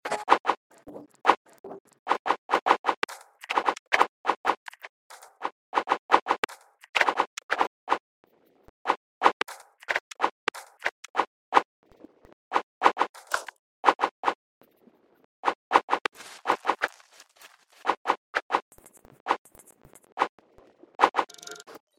Satisfying ASMR...